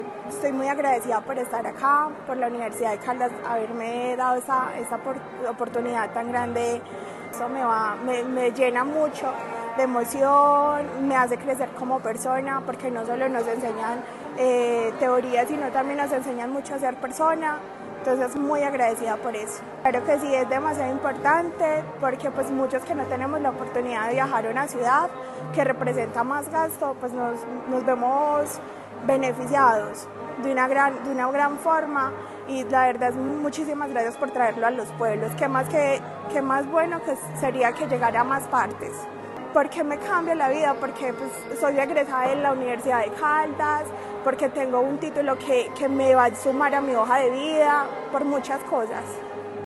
Audio estudiante en Anserma